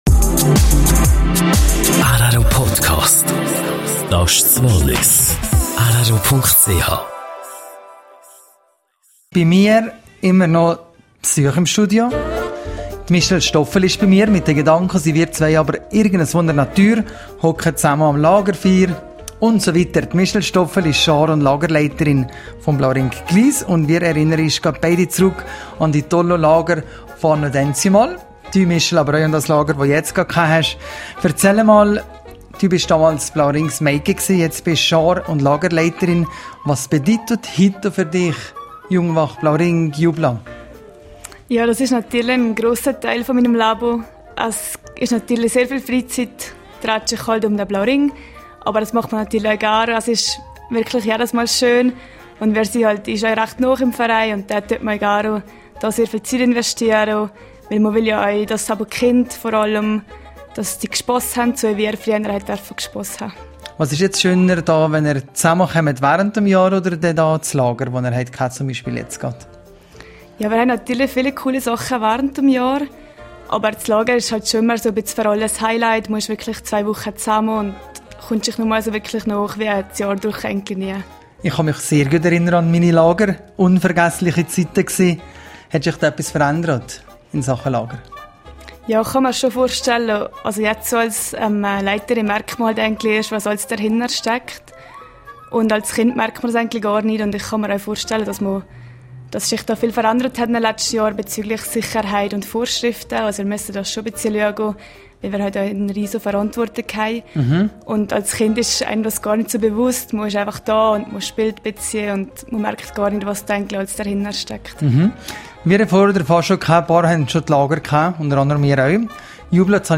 Blauring: Interview